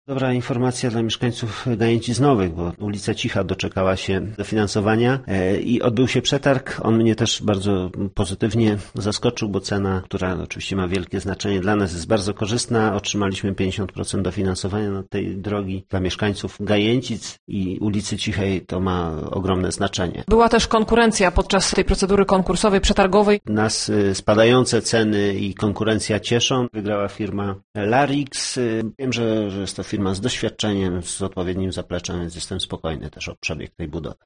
– informował nas burmistrz Pajęczna Piotr Mielczarek.